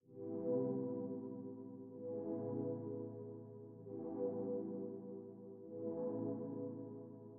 罗非垫
描述：空气中的Lofi垫。
Tag: 130 bpm Hip Hop Loops Pad Loops 1.24 MB wav Key : Unknown